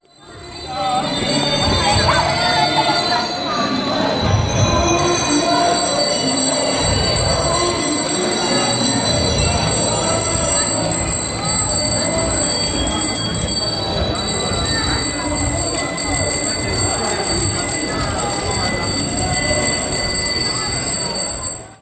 Klingelkonzert der Radfahrenden (Audio 1/1) [MP3]